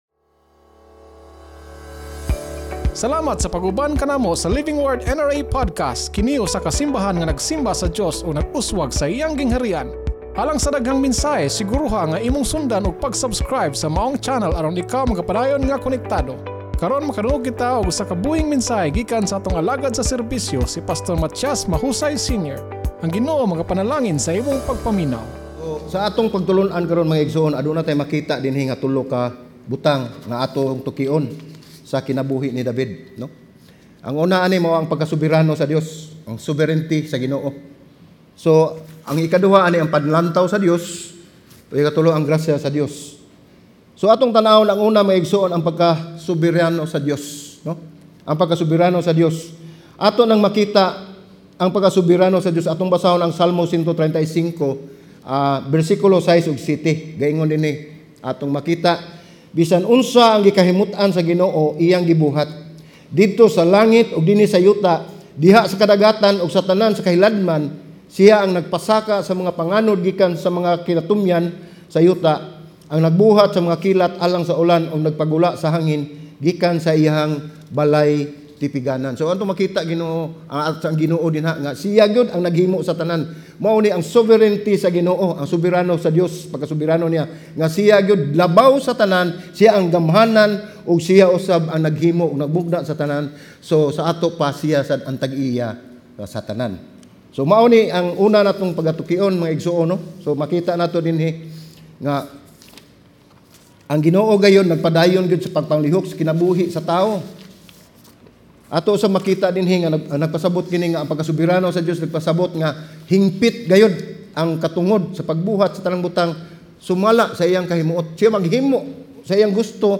Ang Dios nasayod sa sitwasyon ug sa tanang manghitabo sa atong kinabuhi. Sermon